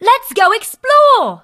bea_start_vo_01.ogg